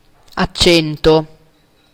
Ääntäminen
IPA : /təʊn/